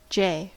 Ääntäminen
IPA: [va.lɛ]